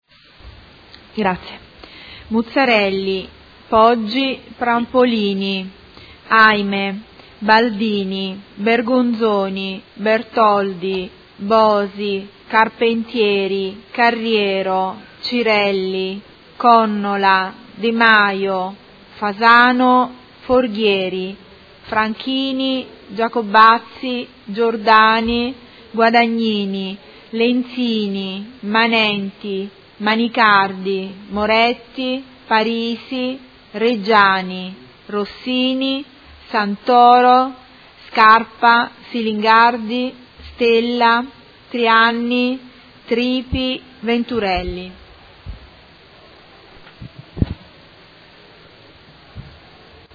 Segretaria Generale — Sito Audio Consiglio Comunale
Seduta del 12/12/2019. Appello